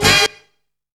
BOX STAB.wav